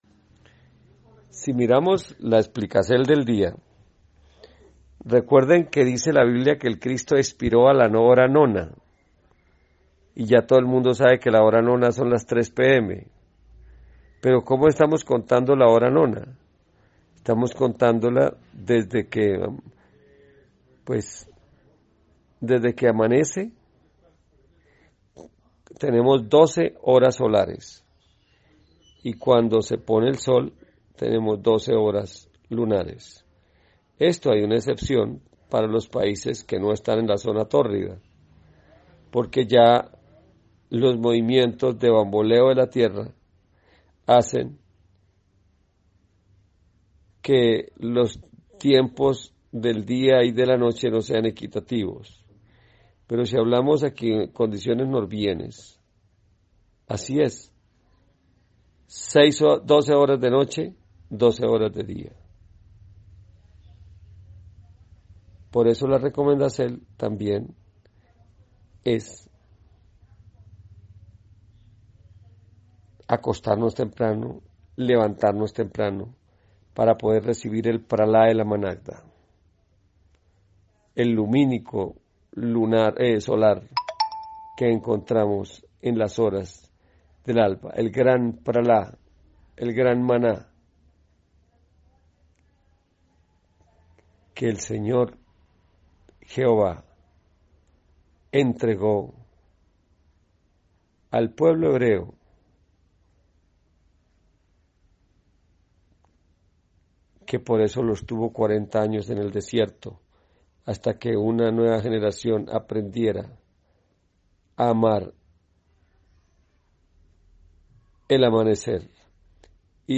UNA GRAN KONFERENSIA SOVRE EL ORIGEN DE LOS DÍAS Y SU VERDADERO ORDEN